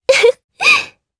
Erze-Vox-Laugh_jp.wav